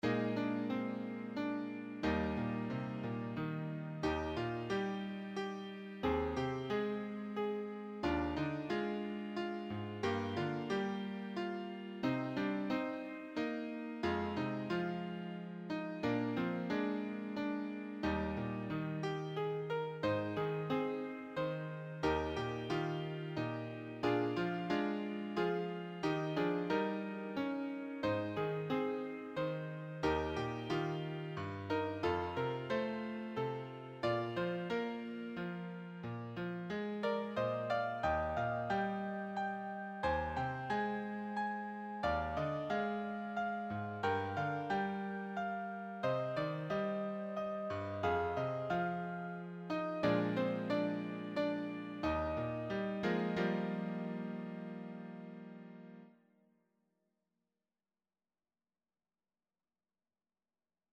Can It Be (Piano Accompaniment)
Can-it-bepianoacc.mp3